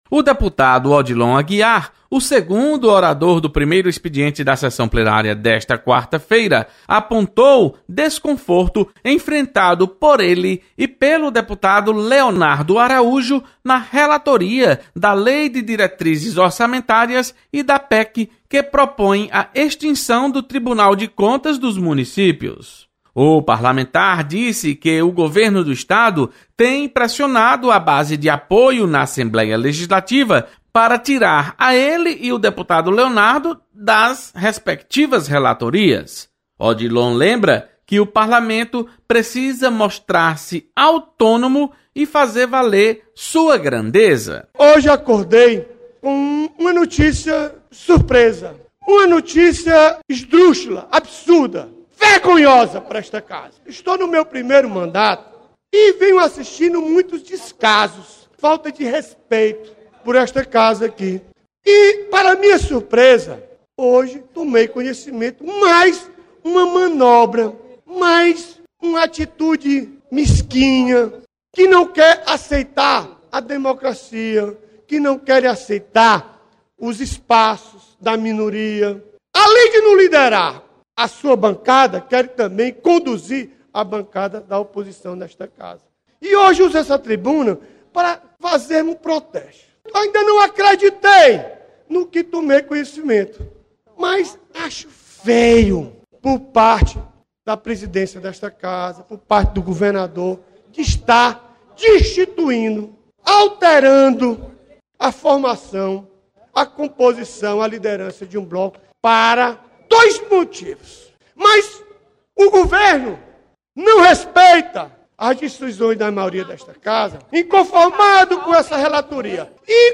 Deputado Odilon Aguiar manifesta preocupação com interferência do Governo do Estado nos trabalhos do Parlamento Cearense. Repórter